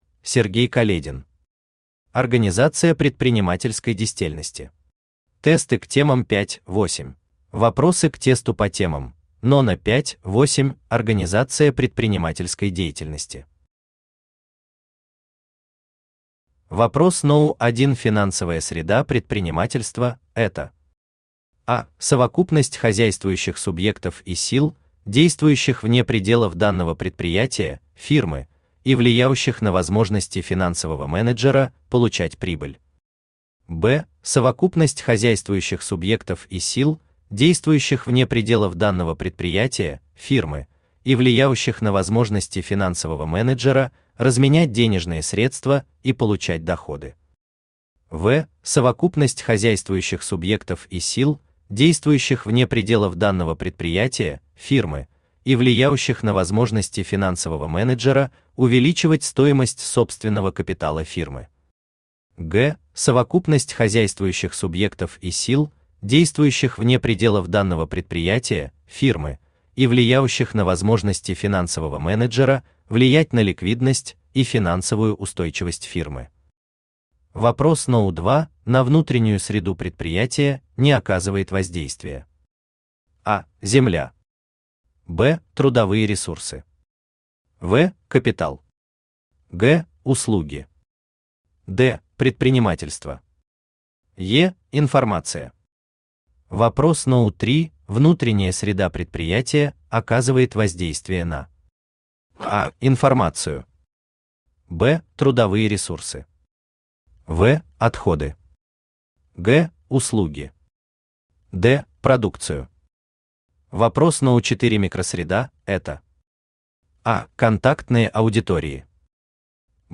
Аудиокнига Организация предпринимательской дестельности. Тесты к темам 5-8 | Библиотека аудиокниг
Aудиокнига Организация предпринимательской дестельности. Тесты к темам 5-8 Автор Сергей Каледин Читает аудиокнигу Авточтец ЛитРес.